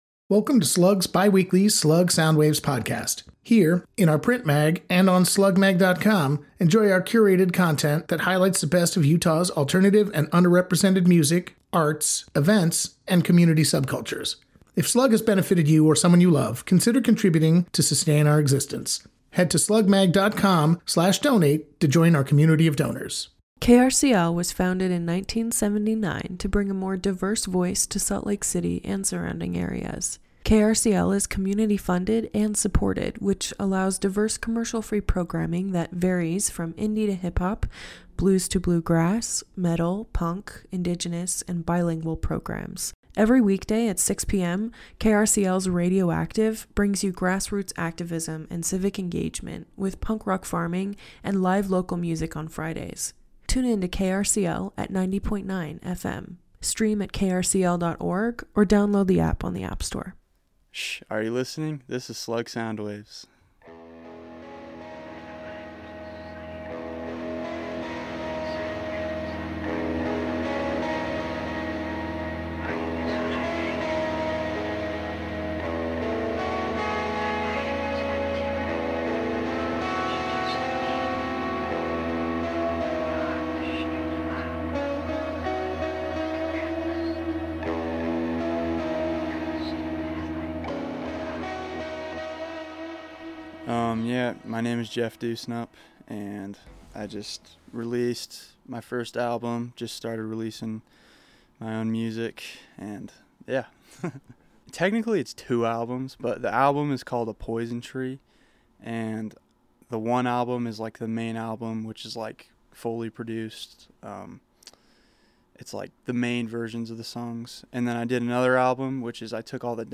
Solo artist
shoegaze, grunge